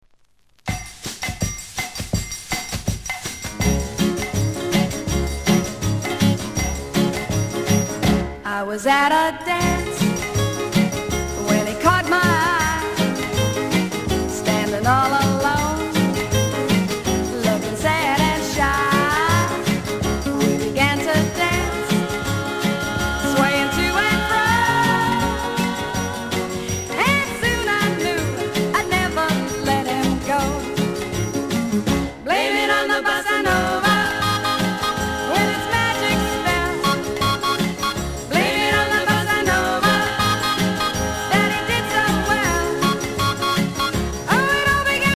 店舗 ただいま品切れ中です お気に入りに追加 1963年、LATIN, BOSSA NOVA!